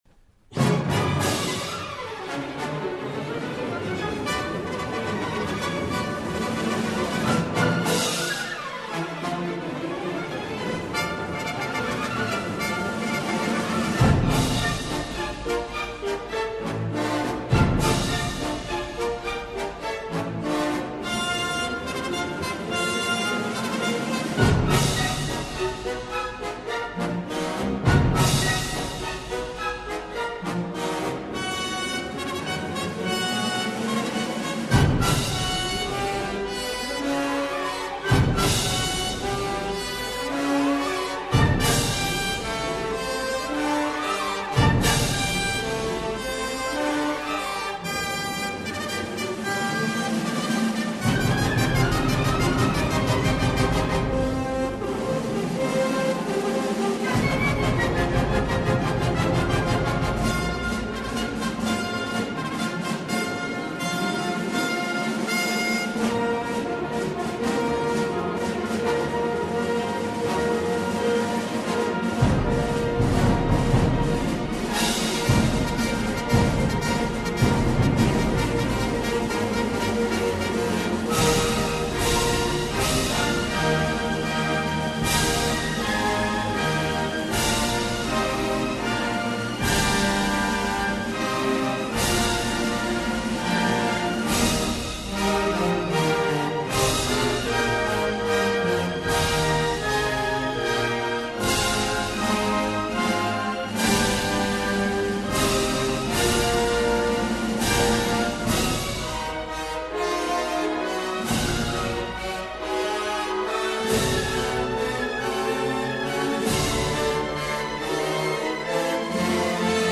файл) 5,11 Мб Чайковский П.И. Опера «Мазепа» (Симфонический антракт «Полтавский бой») 1